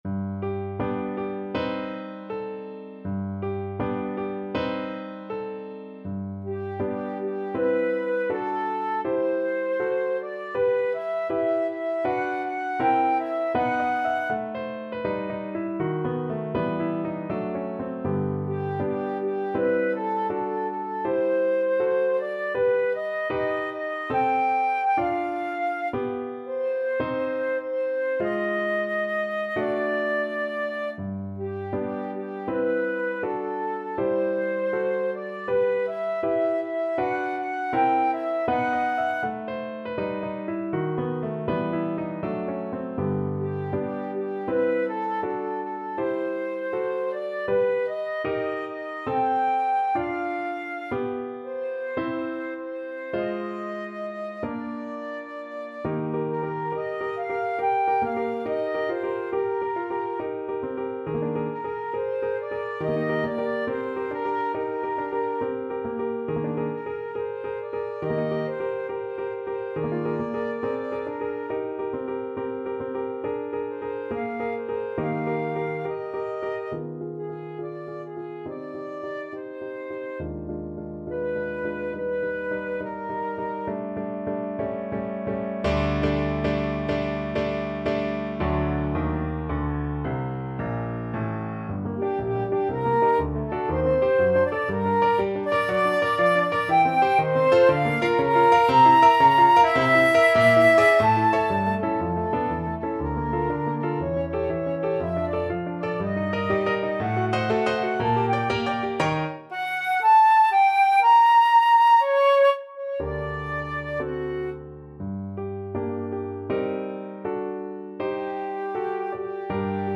Flute
2/4 (View more 2/4 Music)
G major (Sounding Pitch) (View more G major Music for Flute )
Moderato =80
Classical (View more Classical Flute Music)